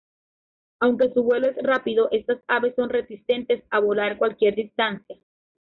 Pronounced as (IPA) /ˈbwelo/